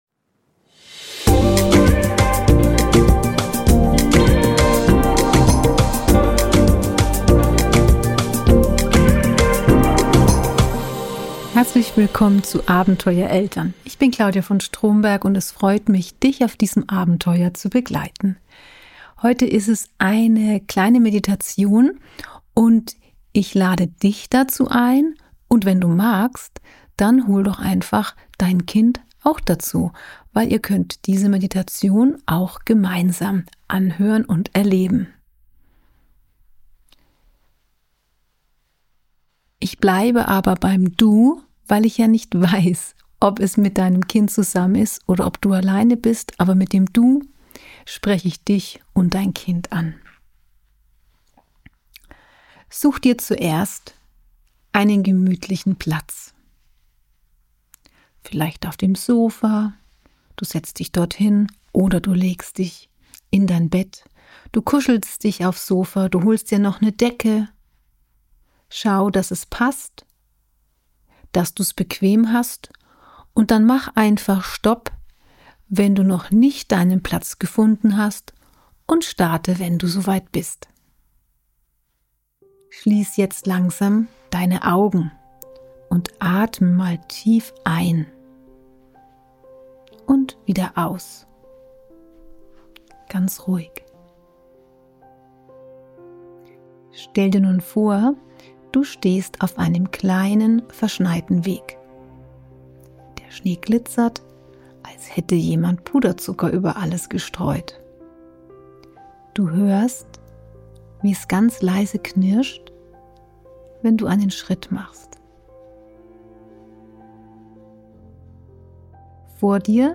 #085_ „Weihnachtszauber: Eine kleine Meditation für dich und dein Kind“